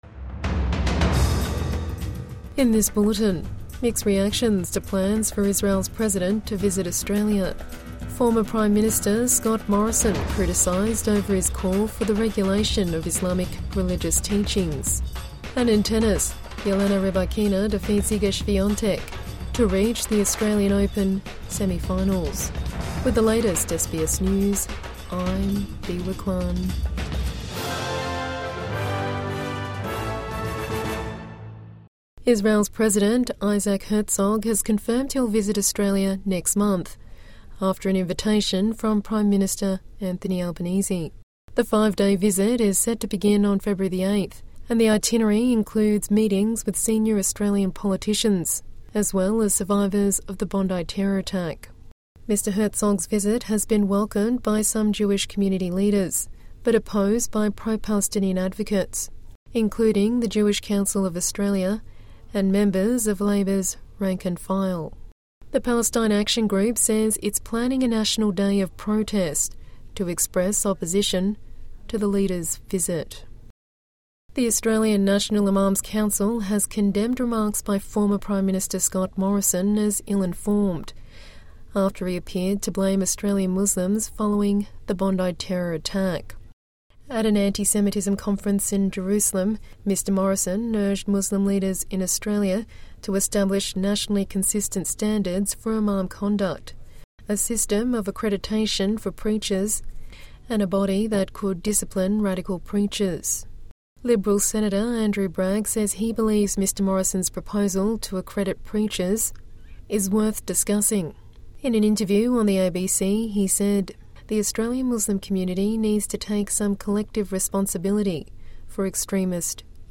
Mixed reactions to plan for Israel's President to visit Australia | Evening News Bulletin 28 January 2026